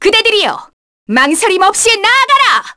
Artemia-Vox_Victory_kr.wav